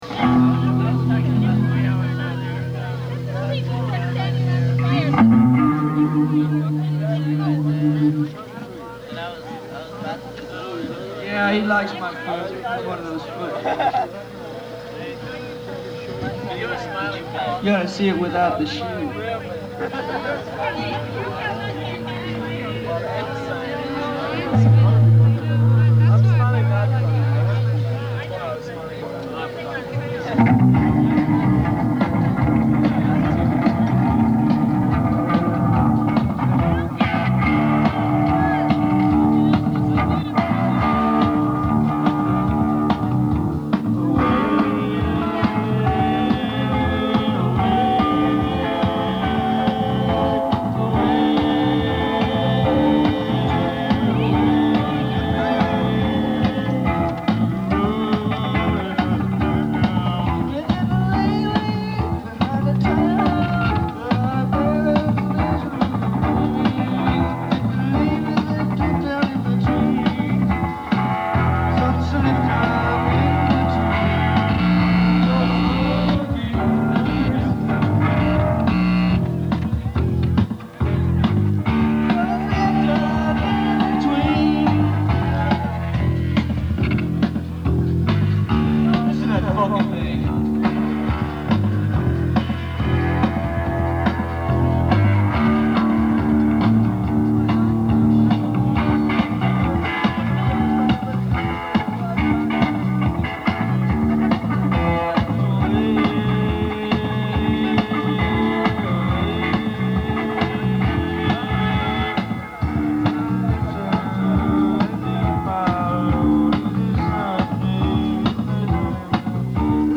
(100 miles east of Victorville, CA)